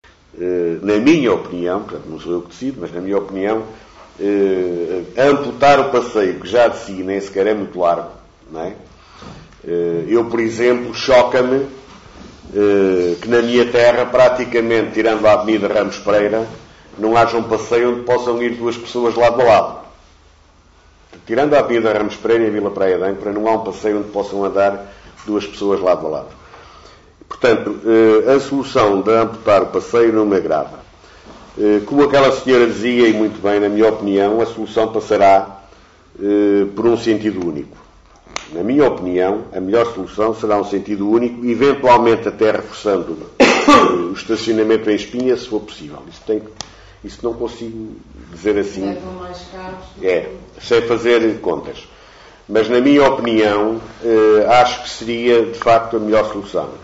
“Comer” um bocado ao passeio aumentando assim a largura da estrada ou transformar a avenida de Santana numa via de sentido único são as soluções que a Câmara está a ponderar como explicou o vereador Guilherme Lagido, responsável pelo pelouro do trânsito.